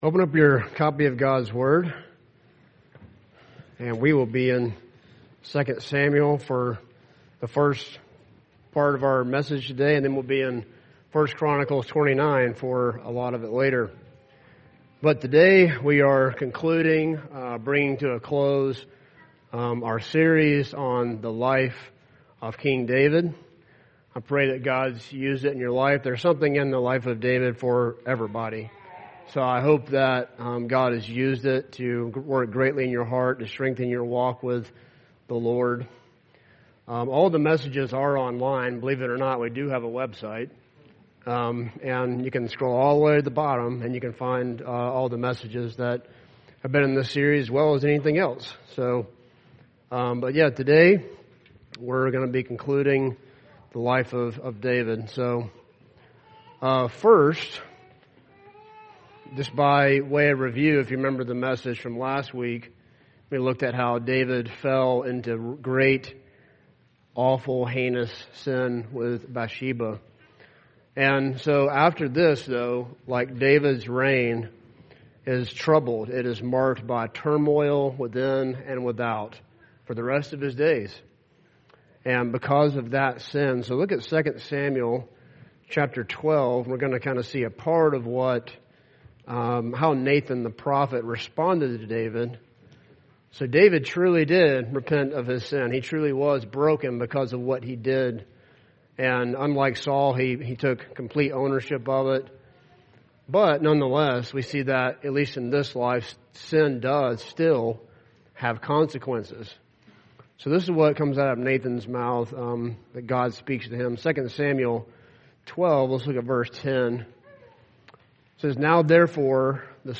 David Passage: 2 Samuel 23:1-7, 1 Chronicles 29:10-22, Psalm 16:1-11 Service Type: Sunday